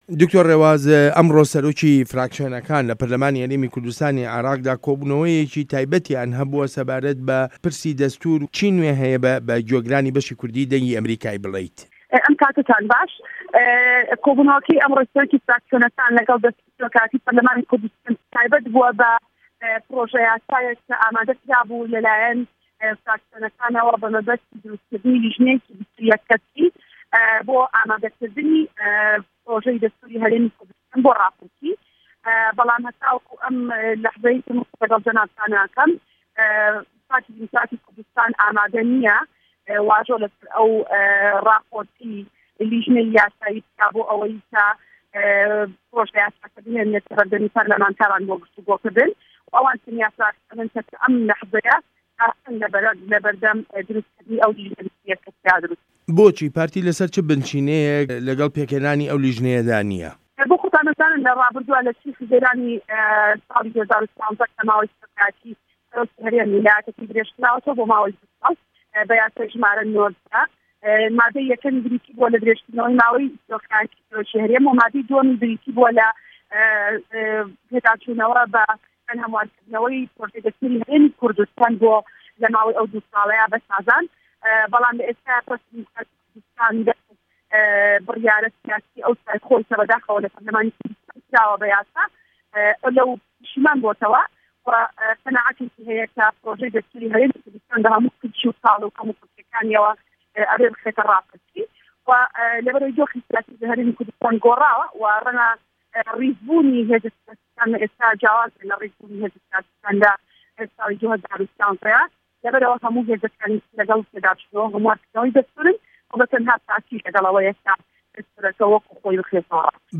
وتووێژ له‌گه‌ڵ دکتۆر ڕێواز فایه‌ق